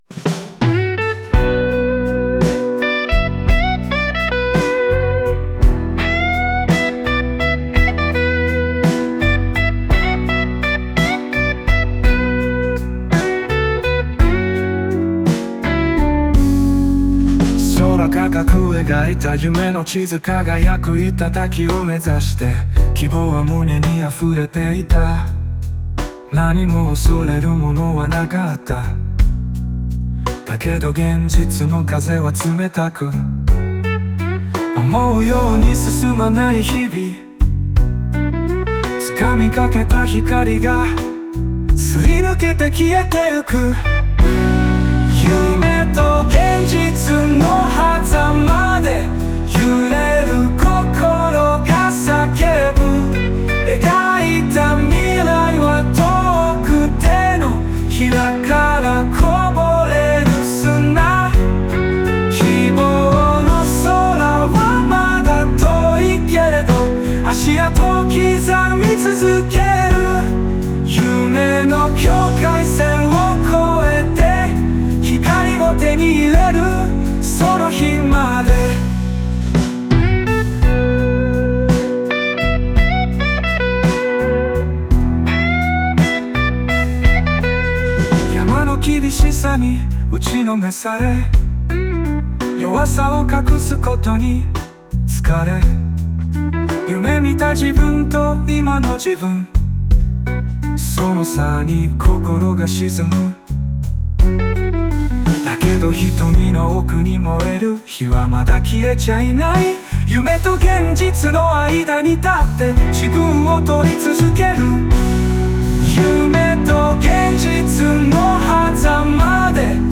オリジナル曲♪
夢と現実の間で揺れながらも歩み続ける姿を、力強くも切ないメロディに乗せて届けたい一曲です。